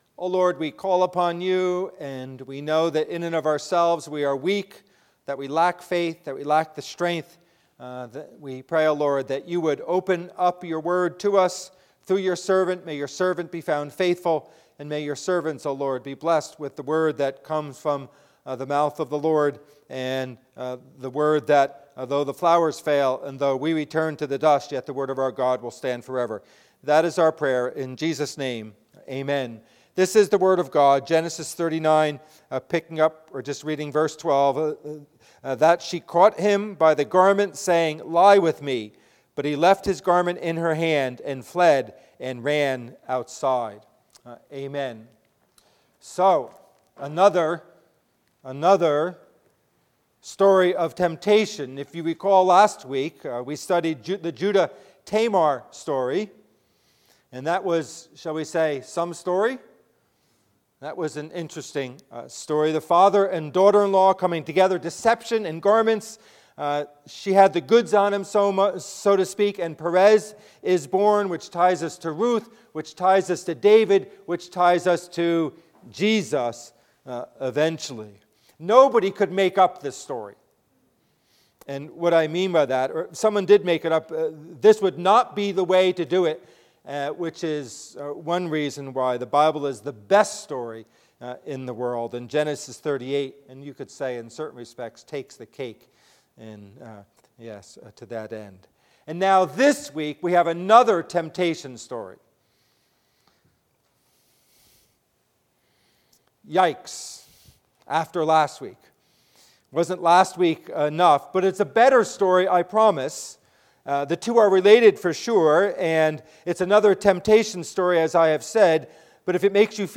Passage: Genesis 39 Service Type: Worship Service